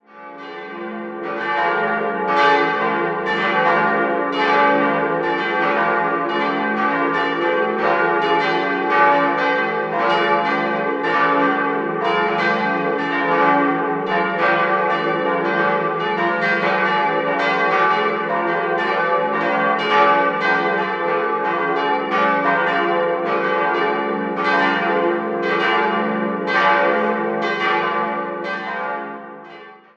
Innenansicht wird noch ergänzt. 4-stimmiges ausgefülltes D-Moll-Geläute: d'-f'-g'-a' Die große Glocke wurde 1871 von Eduard Becker in Ingolstadt gegossen, die drei kleineren stammen von Karl Czudnochowsky aus dem Jahr 1955.